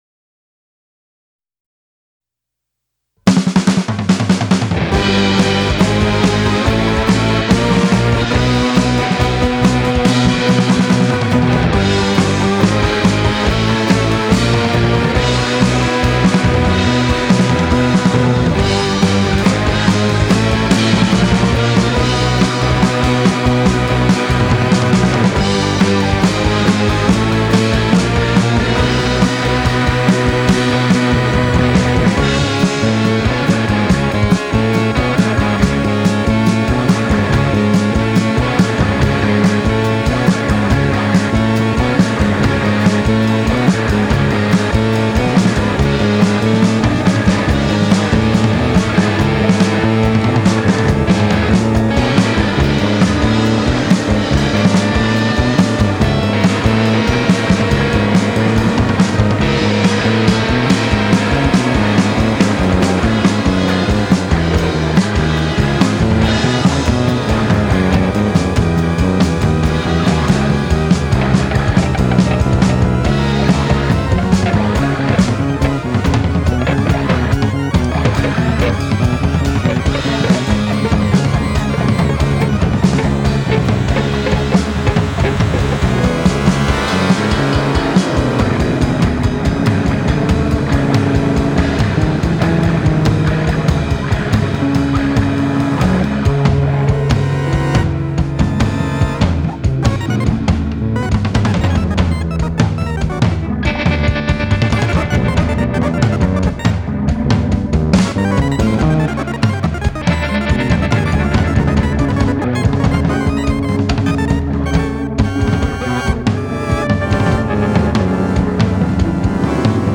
Genres: Rock